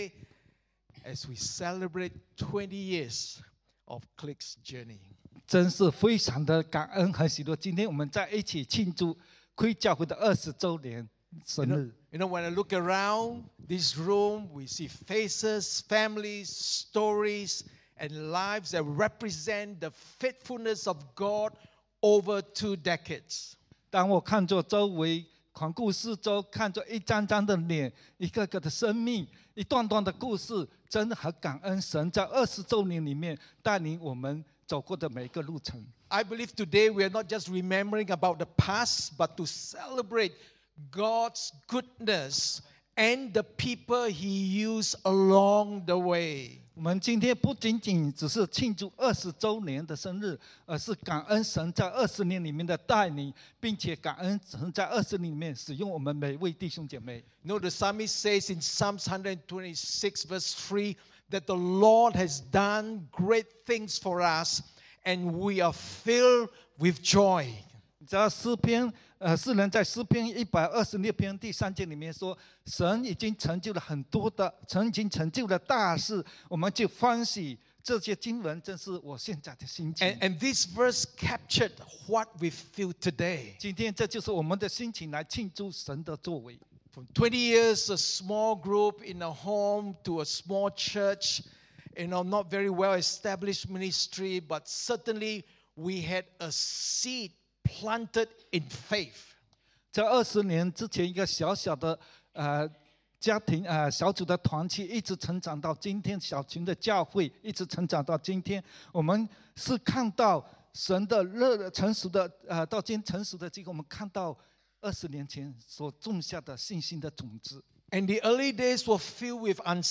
Download Download Sermon Notes The Road Ahead.pptx From this series Current Sermon The Road Ahead Celebrating 20 years of God's Faithfulness